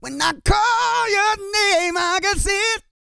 E-GOSPEL 112.wav